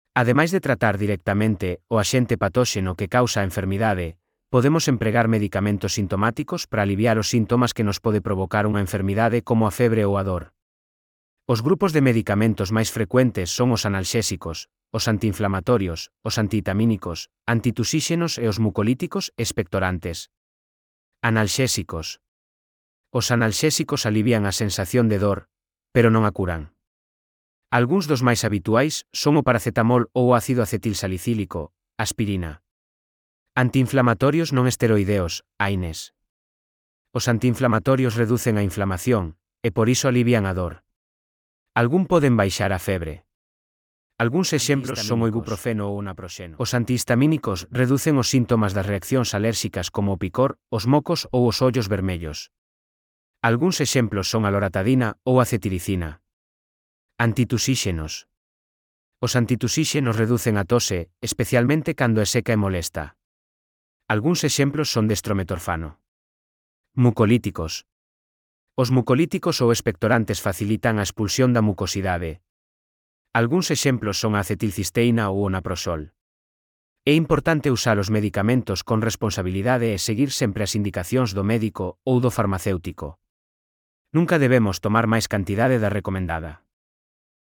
Elaboración propia coa ferramenta Narakeet. Audio (CC BY-SA)